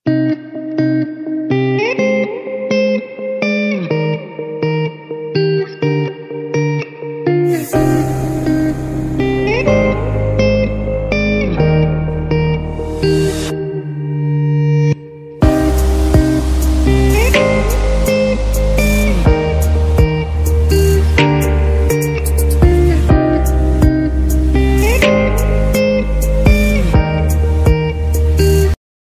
English Ringtones